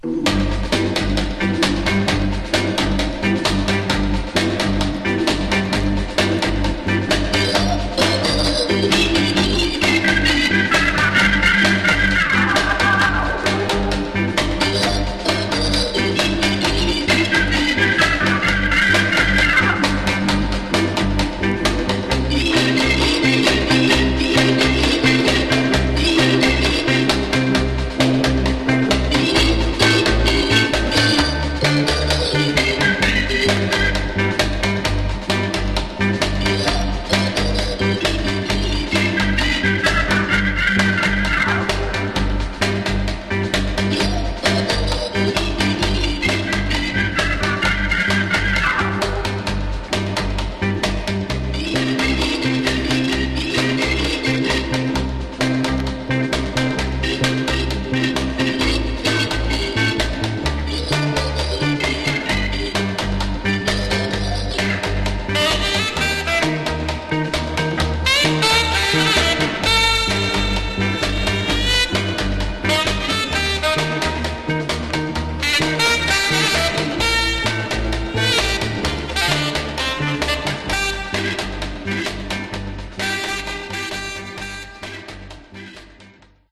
Genre: Soul Instrumentals